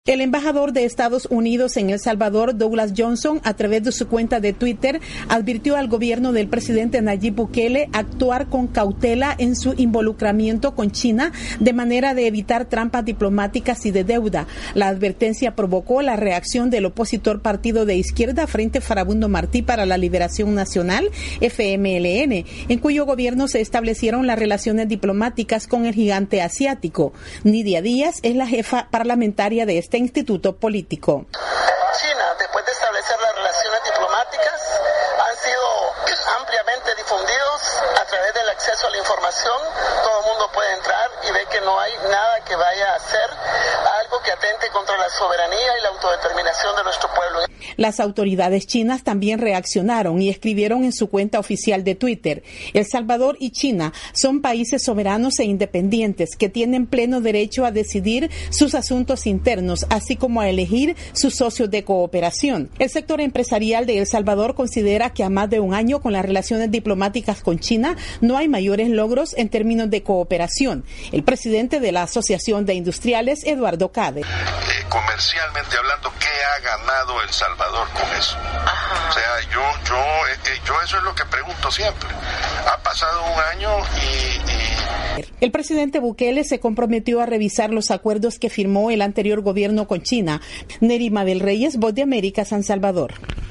VOA: Informe de El Salvador